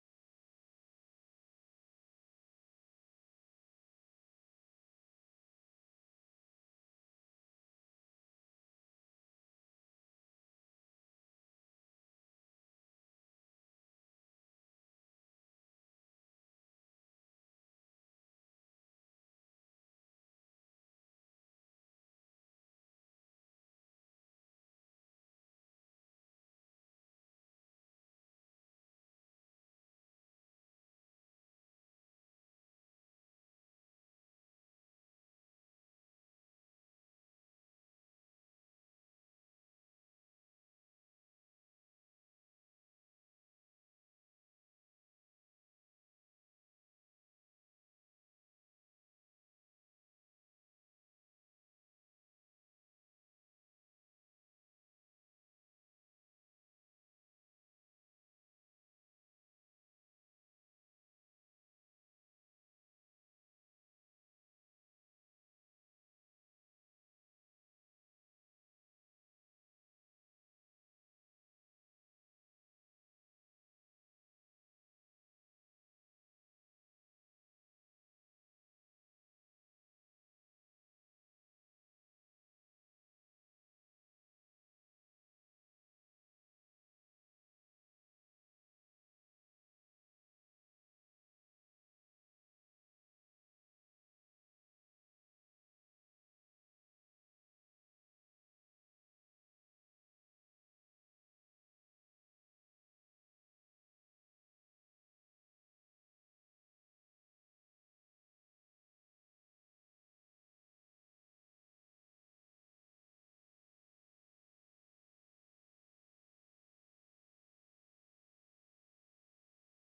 Live from Fridman Gallery